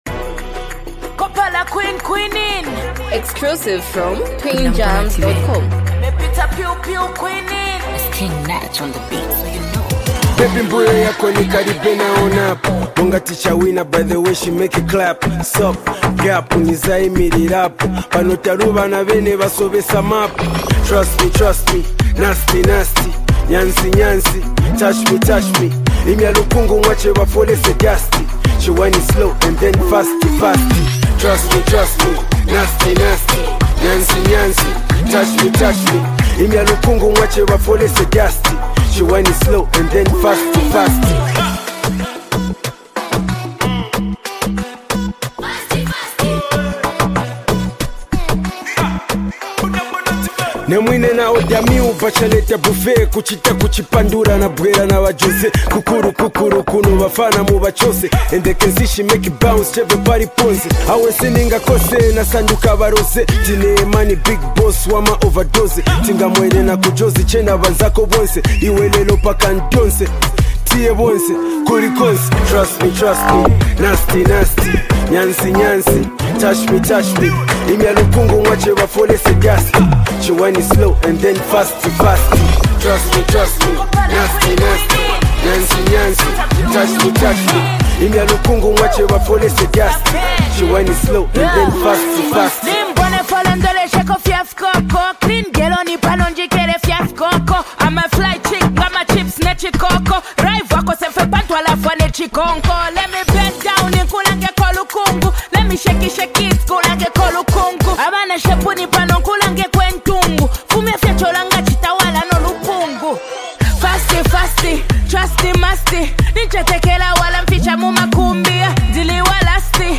Highly multi talented act and super creative rapper